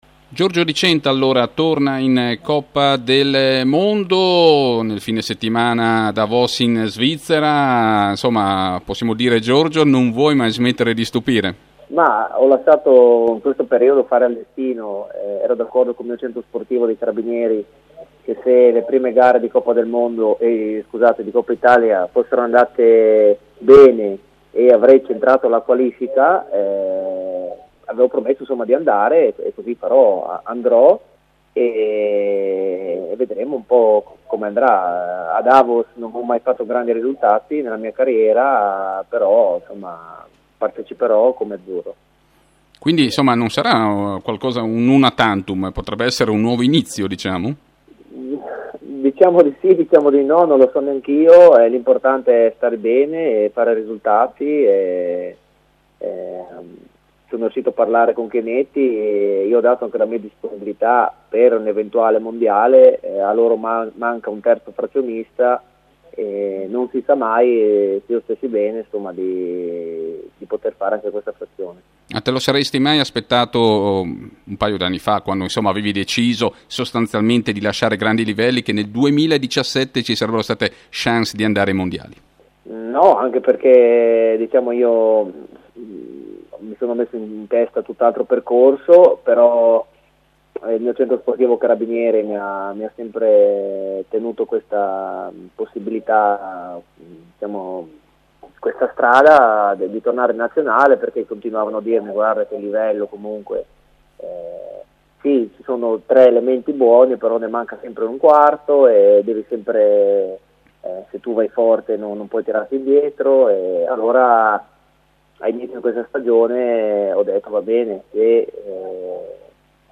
L’AUDIOINTERVISTA